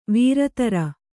♪ vīratara